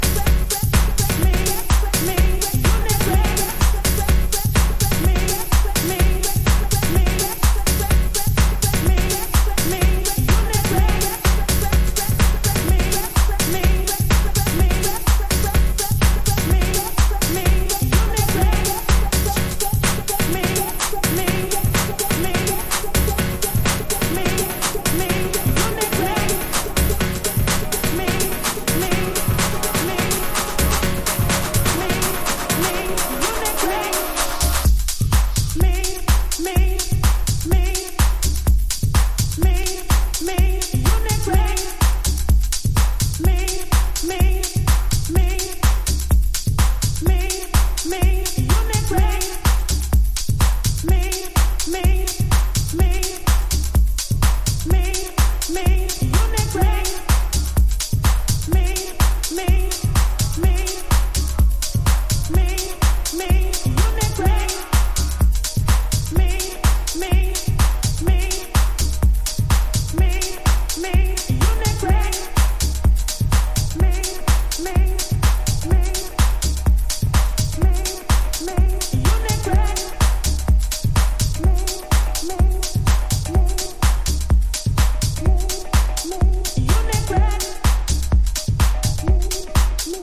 ヴォーカルのリピートがクセになるレイヴなリフ！
DEEP HOUSE / EARLY HOUSE# ELECTRO HOUSE / TECH HOUSE